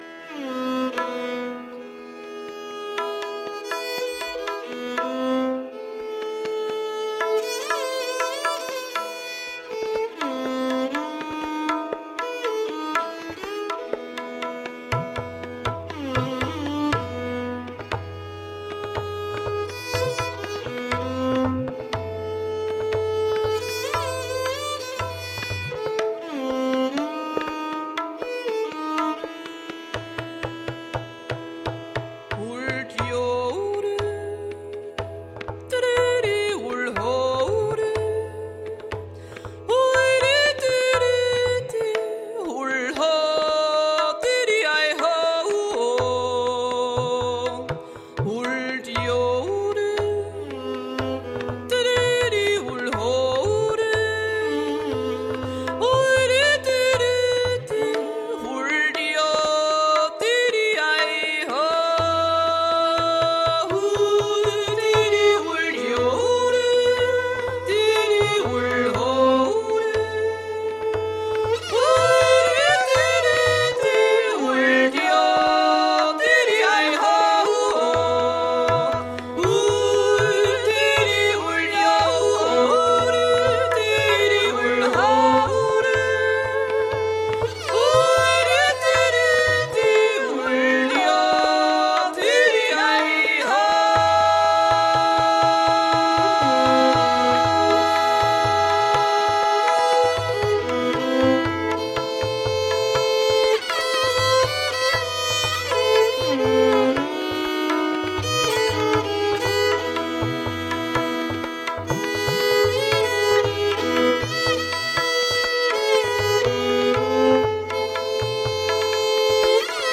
Tagged as: World, Folk, World Influenced